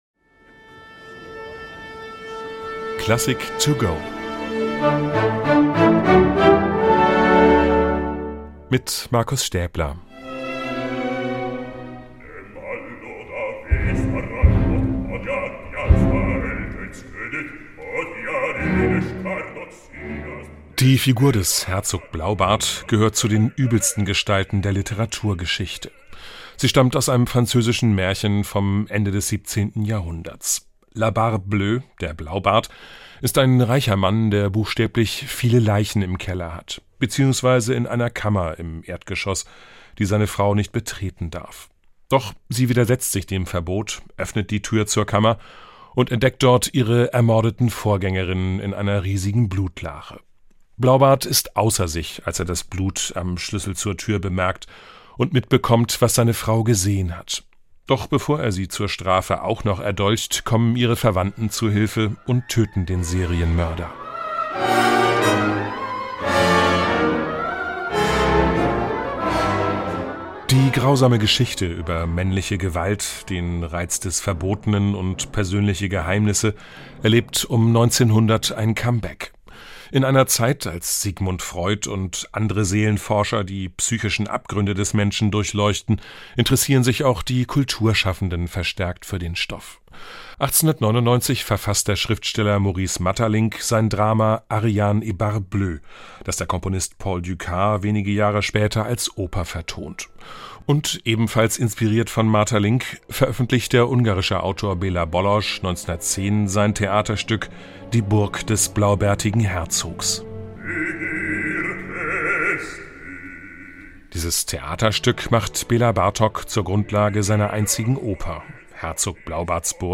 Kurzeinführung für unterwegs.